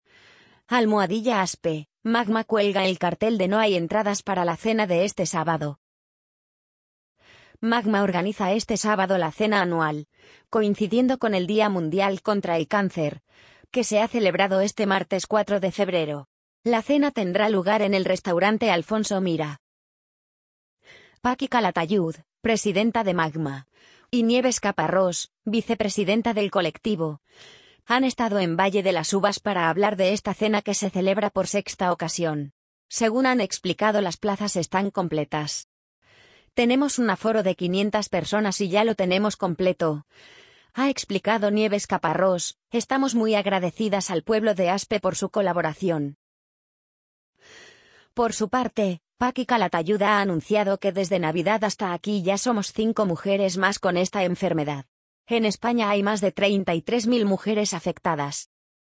amazon_polly_40181.mp3